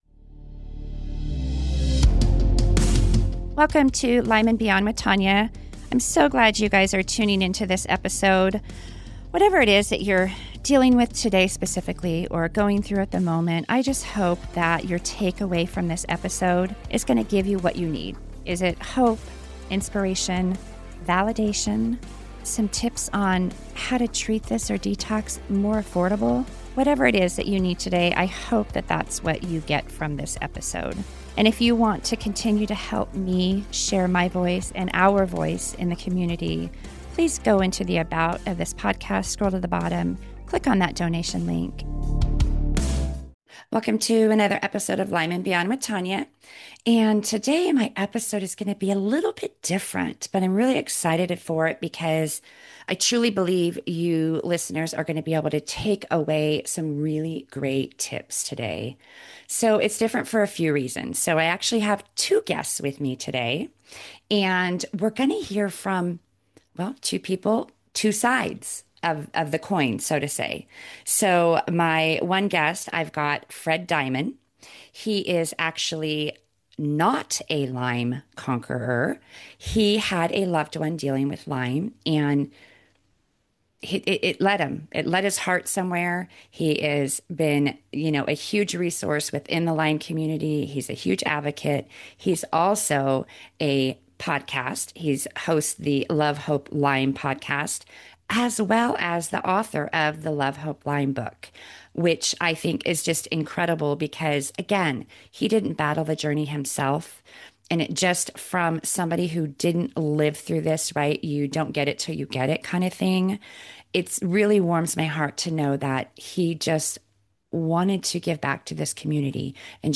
Together, we have an honest and supportive conversaton about navigating Lyme disease from both sides of the journey.